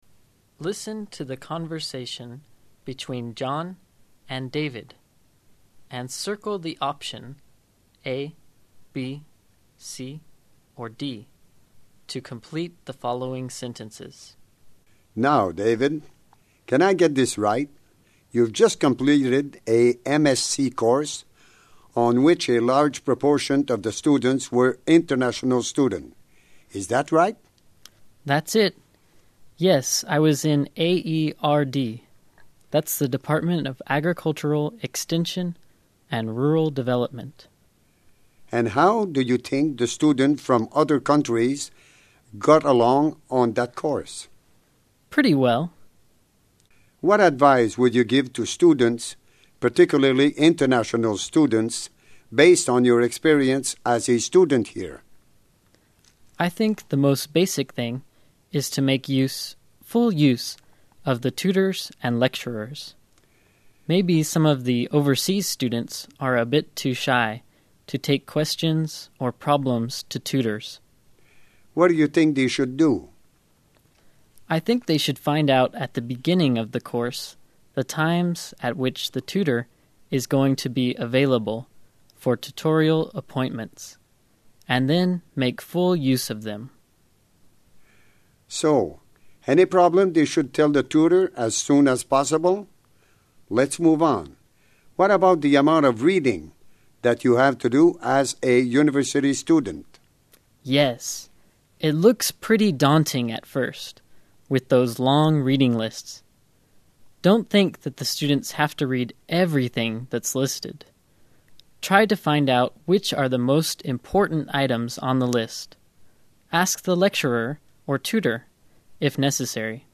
Listen to the conversation between John and David and circle the best option (A B, C, or D) to complete the following sentences.